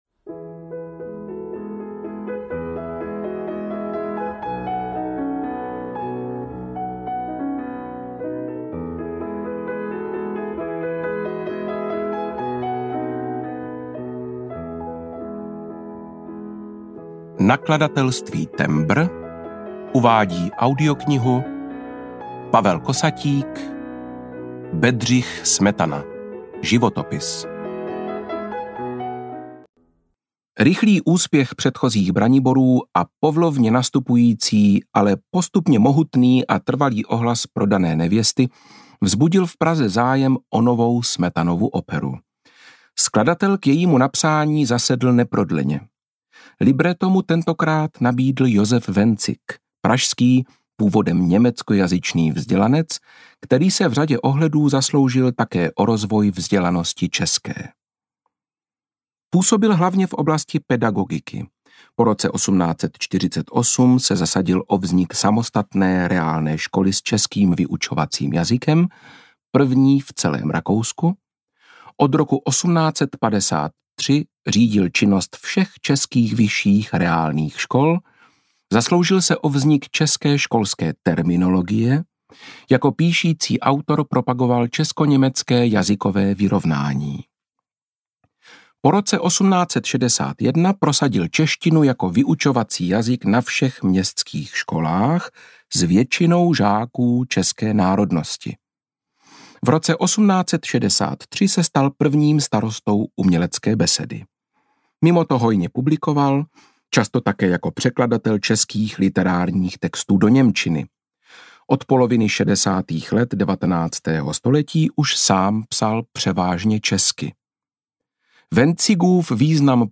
Bedřich Smetana – Životopis audiokniha
Ukázka z knihy
• InterpretJakub Hrůša